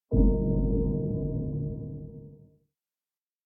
标题出现音效.ogg